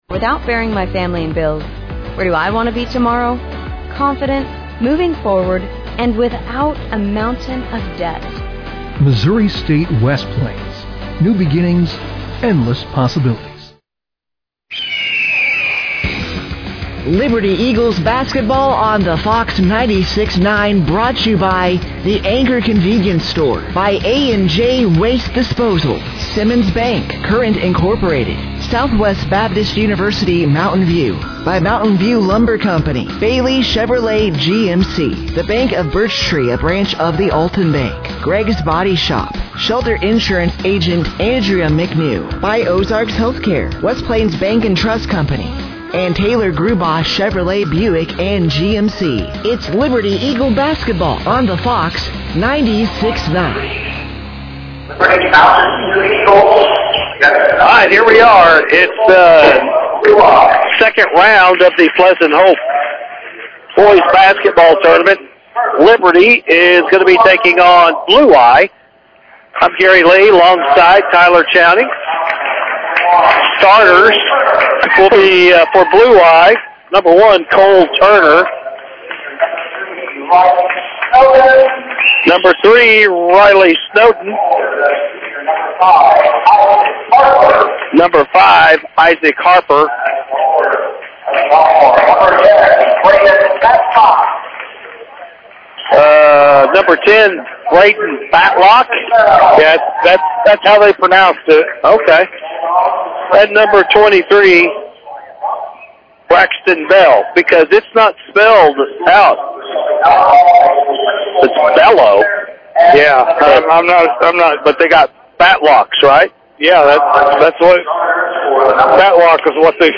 The Liberty Eagles Boys Basketball Team traveled back to Pleasant Hope on Thursday Night, January 15th, 2026 after their 80-27 win over The New Covenant Warriors on Tuesday Night.